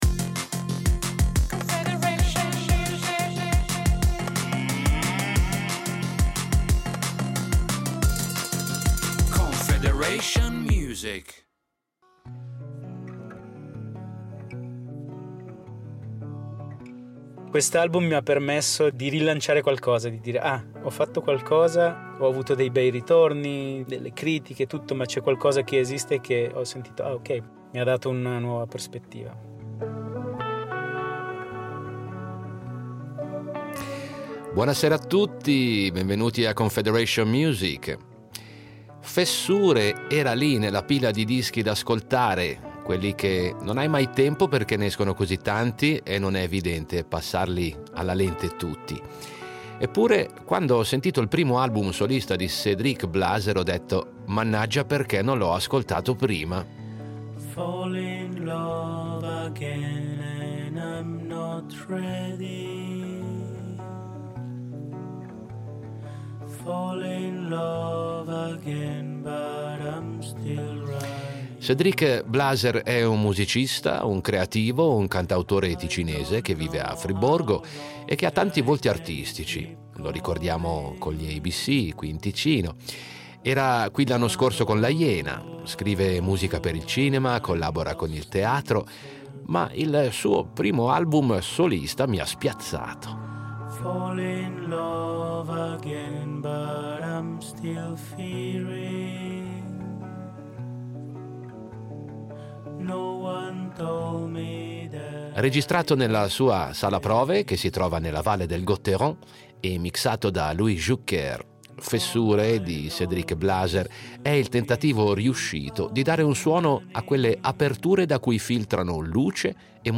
Musica pop
loop station
POP & ROCK